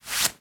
pull-cloth.wav